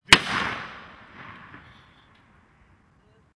Descarga de Sonidos mp3 Gratis: disparo 15.